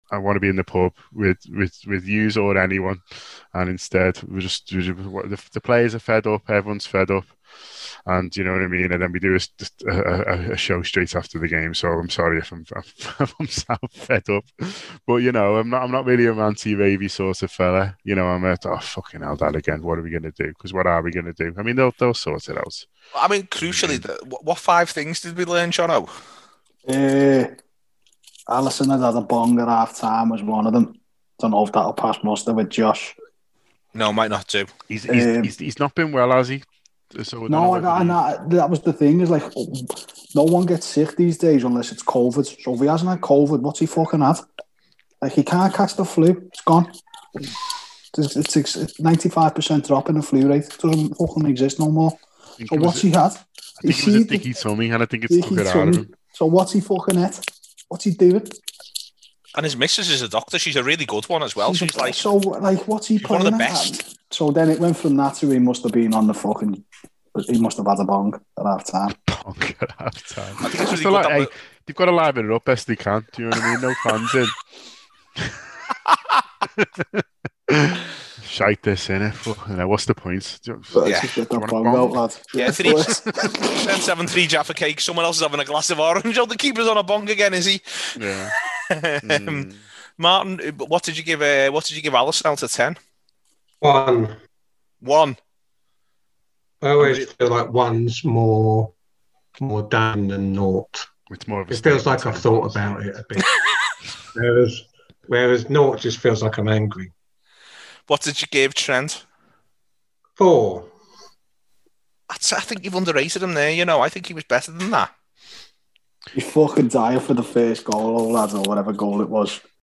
by | Feb 7, 2021 | app, Podcast, Post-Match Show, TAW Player | 0 comments